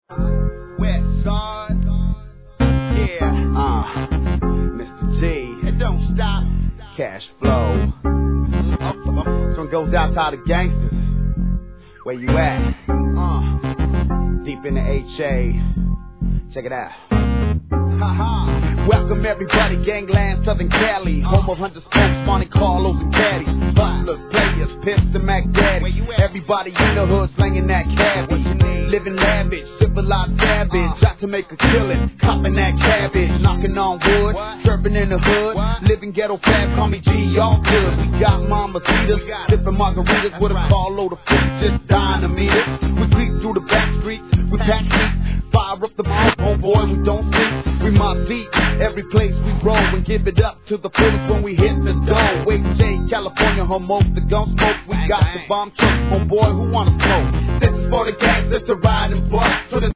G-RAP/WEST COAST/SOUTH
フィメール・ヴォーカルを絡めたメロ〜ナンバー!